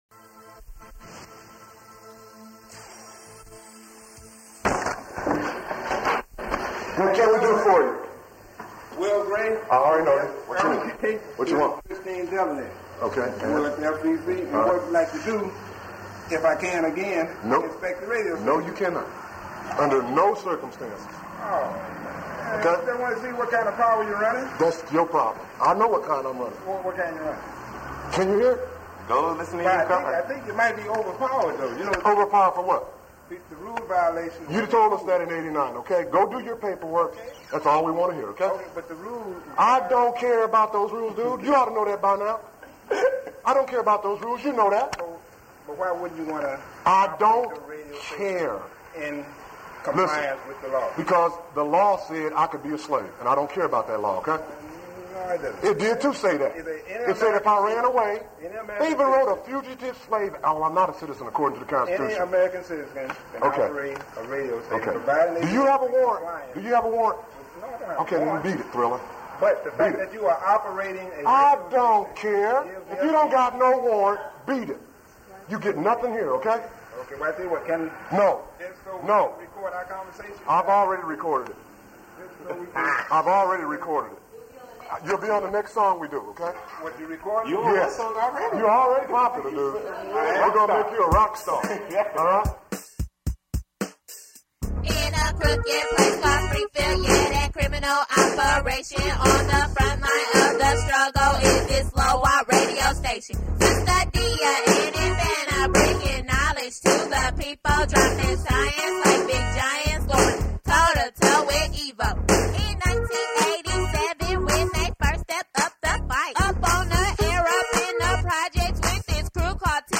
Armed solely with a simple keyboard